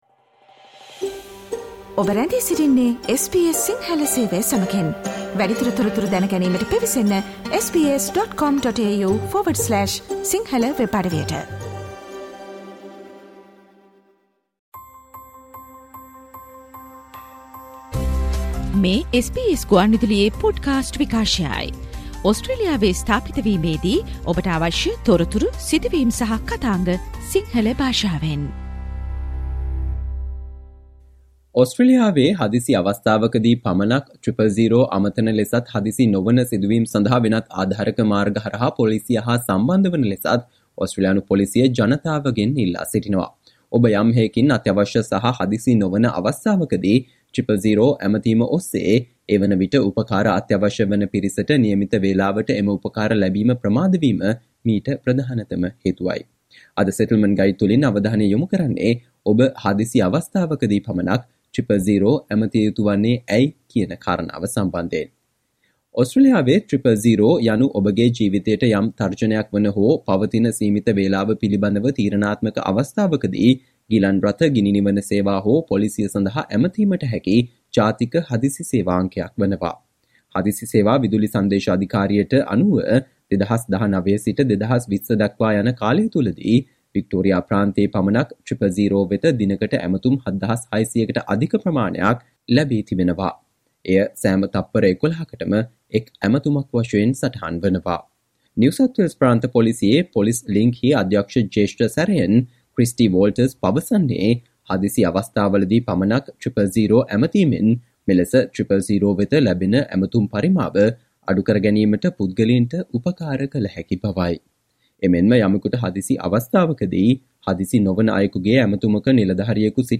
අද මෙම Settlement Guide ගුවන්විදුලි විශේෂාංගයෙන් අපි ඔබ වෙත ගෙන ආ තොරතුරු, කියවා දැන ගැනීමට හැකි වන පරිදි වෙබ් ලිපියක් ආකාරයටත් අපගේ වෙබ් අඩවියේ පලකොට තිබෙනවා.